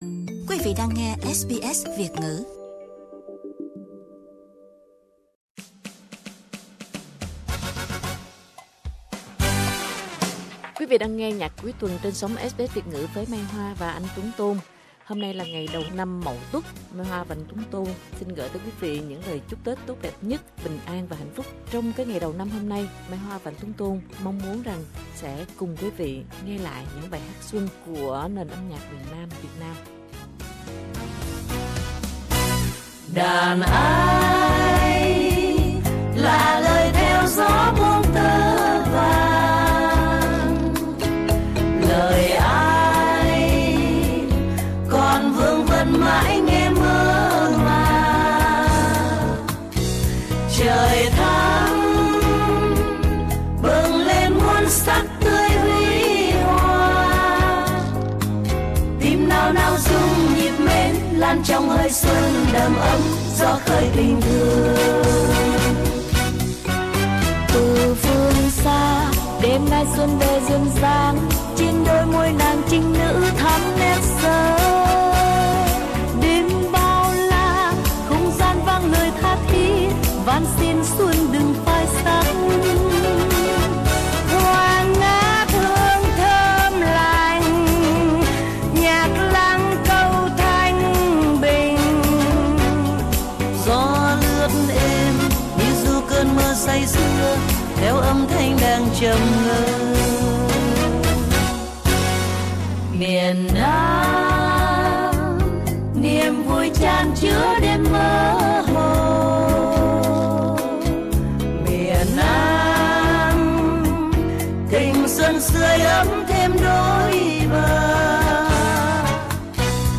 xuân ca
phần hát phối khá hiếm hoi
giọng ca tha thiết